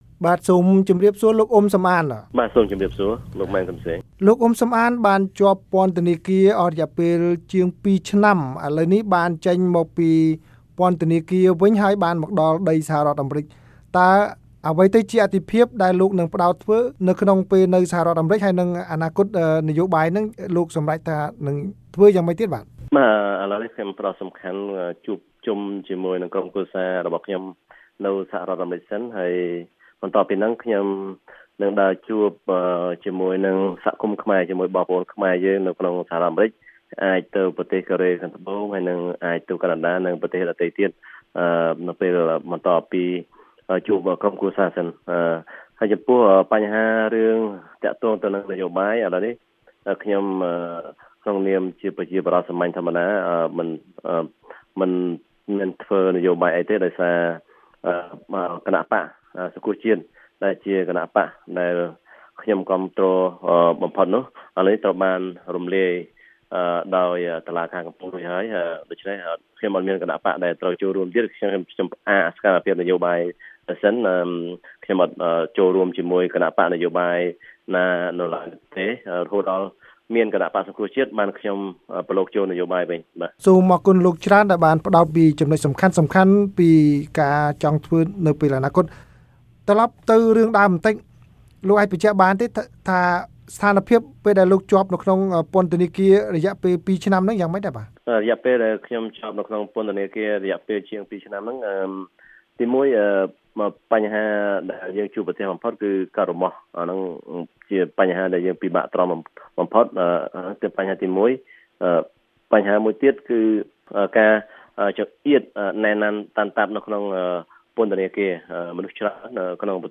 បទសម្ភាសន៍ VOA៖ លោកអ៊ុំ សំអាន ផ្អាករិះគន់បញ្ហាព្រំដែនតែសកម្មក្នុងការងារសហគមន៍